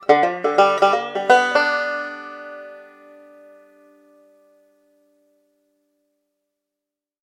Звуки банджо
Перебираем струны пальцами